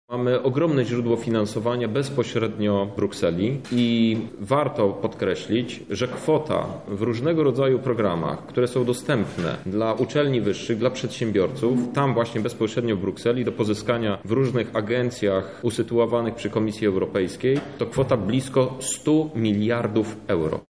– mówi Krzystof Hetman, poseł do parlamentu europejskiego.